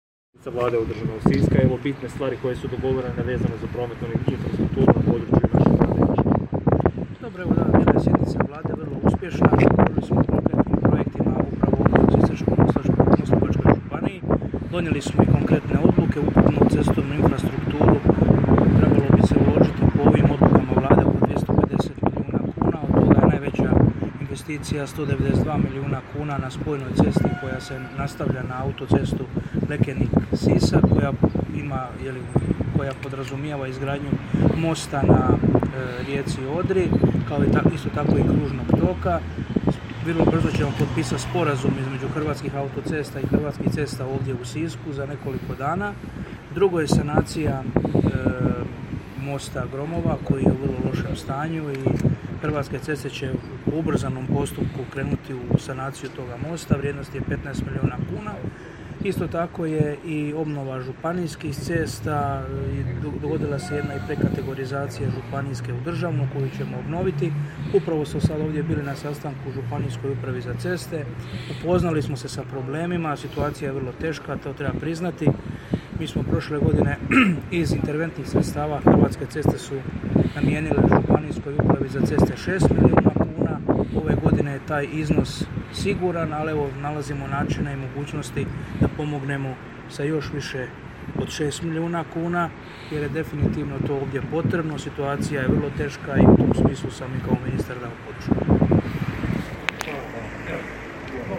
Izjavu koju je u ovoj prigodi dao ministar Butković možete poslušati ovdje: